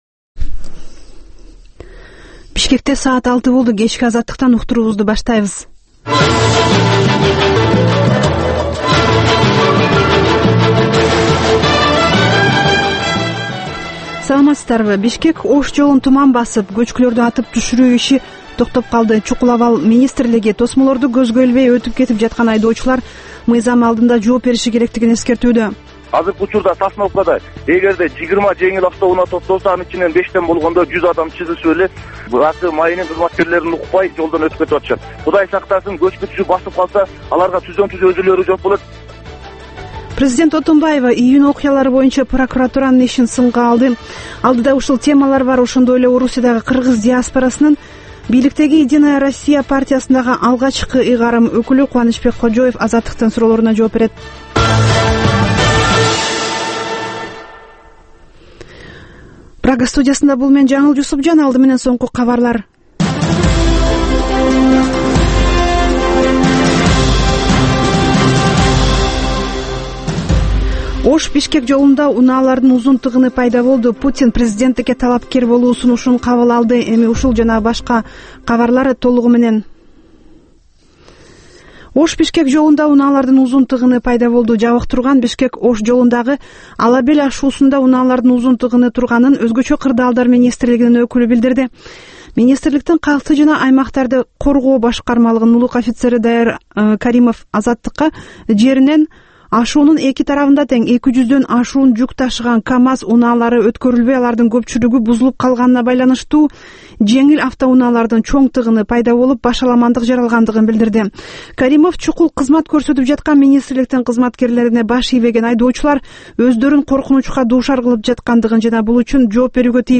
Кечки 6дагы кабарлар